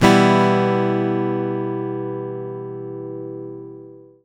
OVATION E-MO.wav